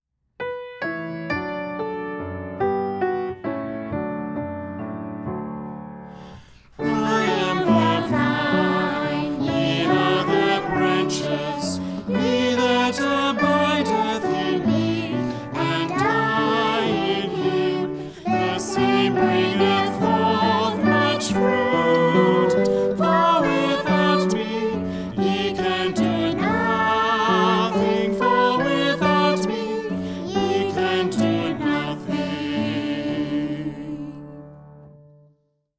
• 26 songs and 25 dialogue recordings.